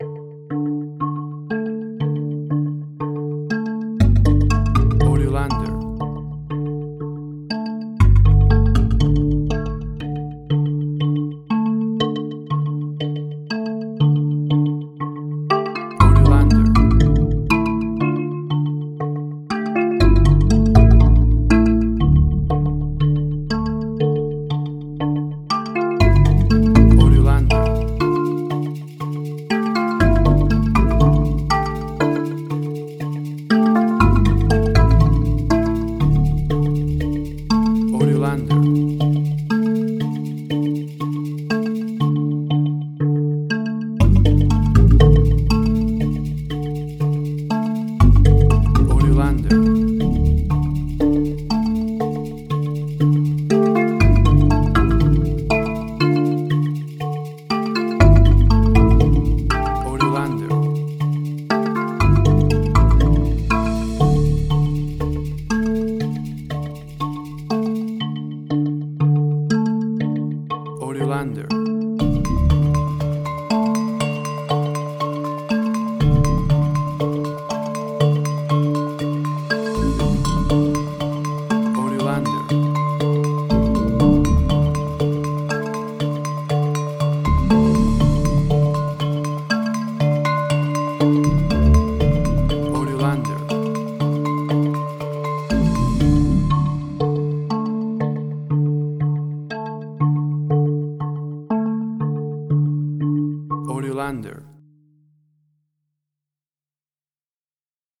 Suspense, Drama, Quirky, Emotional.
WAV Sample Rate: 16-Bit stereo, 44.1 kHz
Tempo (BPM): 120